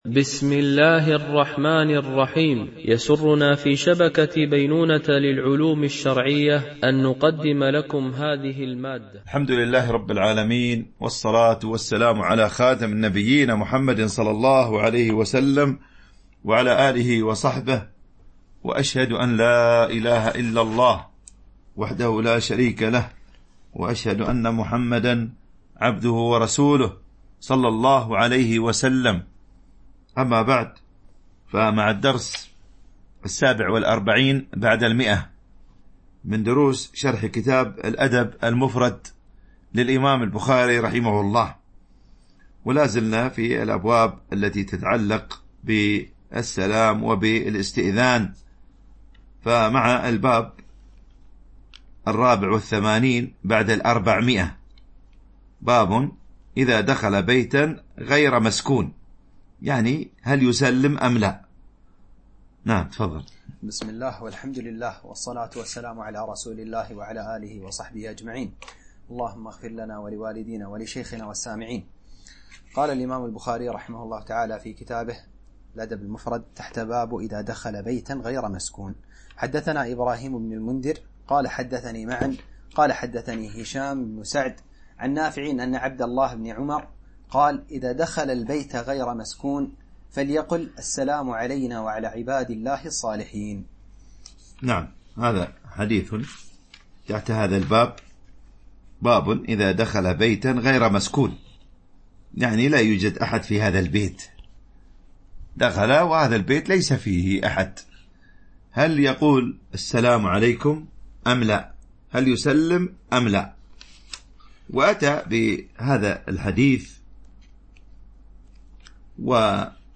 شرح الأدب المفرد للبخاري ـ الدرس 147 ( الحديث 1055 - 1065 )